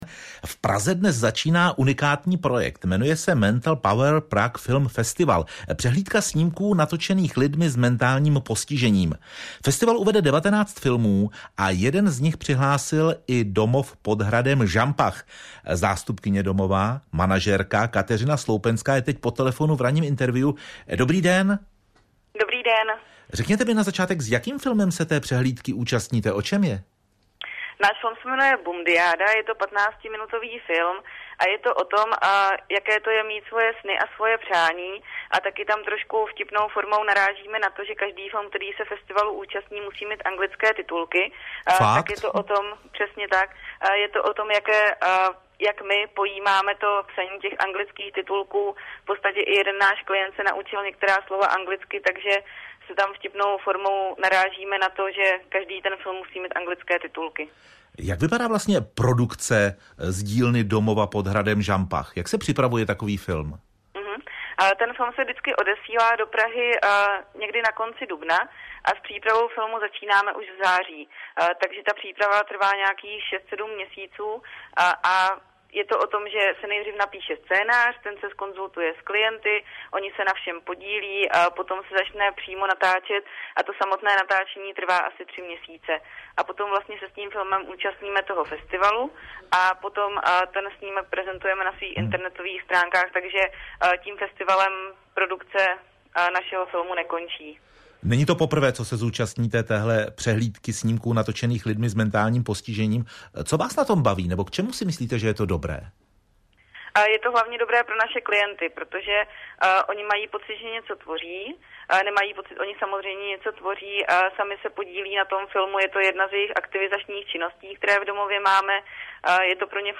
zpráva - Český rozhlas                 zpráva na www - Český rozhlas        FILM: „Bumdyjáda“